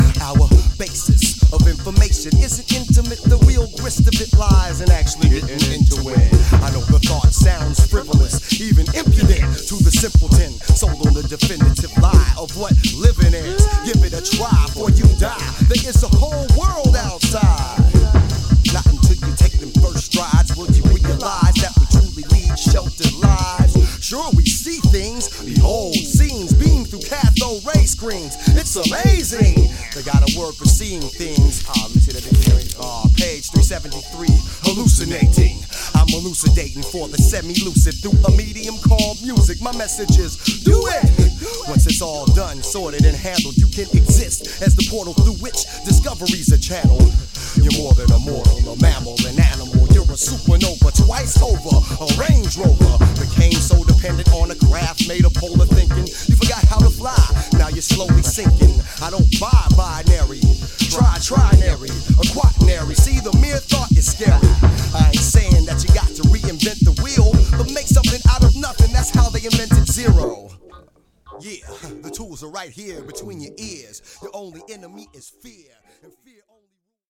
rhymes
beats